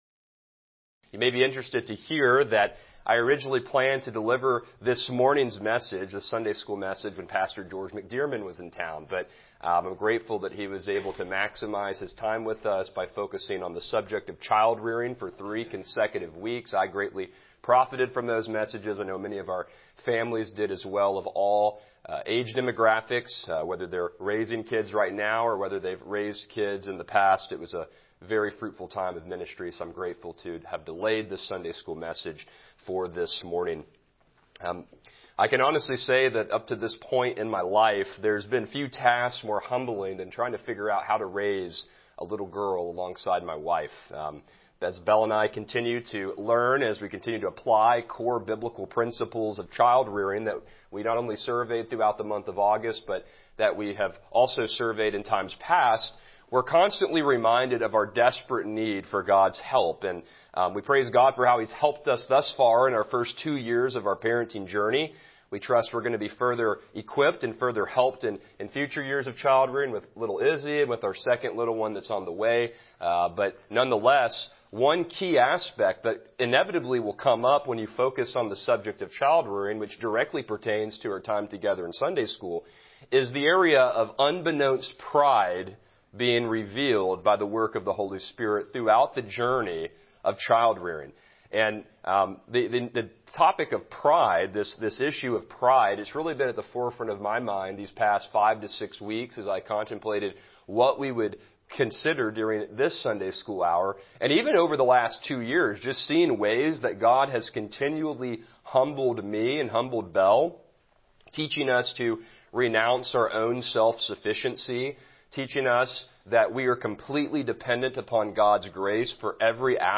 Passage: Daniel 4:28-37 Service Type: Sunday School